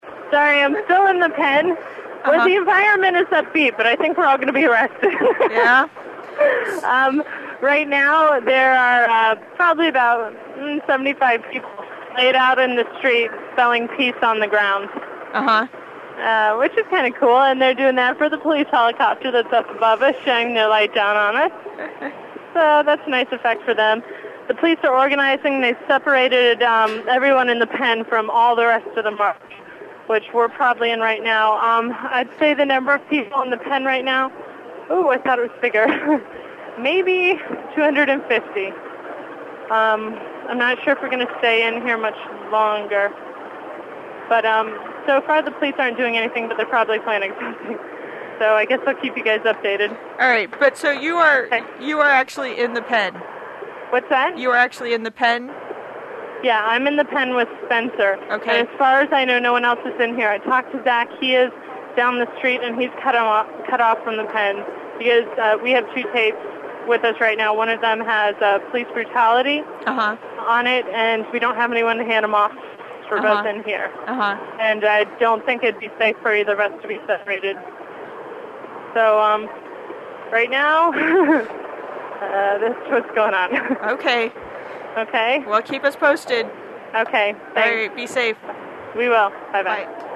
In a pen with 250 other marchers